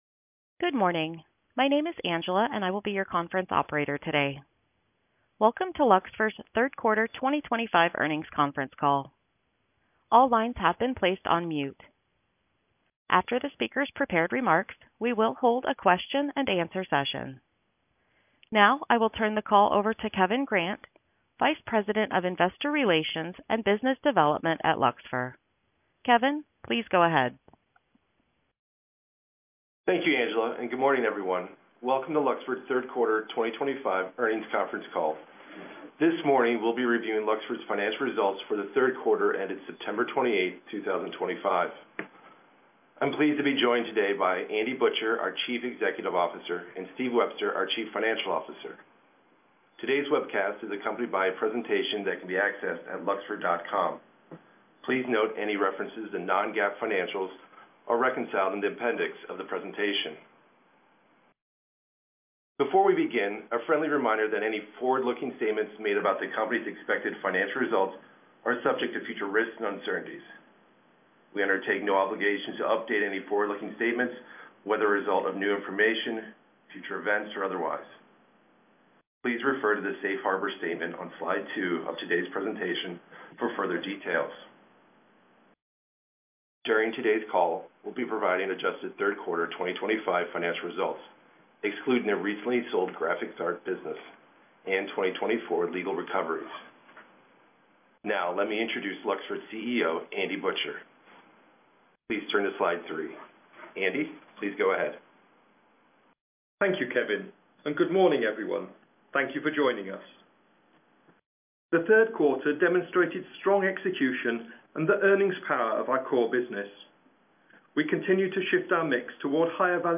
2023 Q4 earnings Call Audio Replay (Opens in a new browser window)